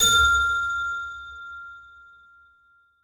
Ding 01 F
bell ding glass sound effect free sound royalty free Sound Effects